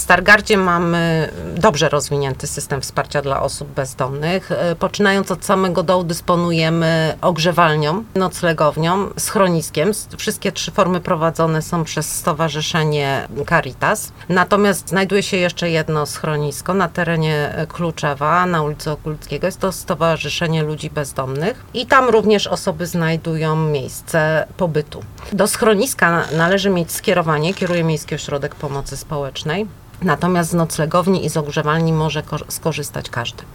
O systemie wsparcia mówiła w audycji „Stargard Mówi”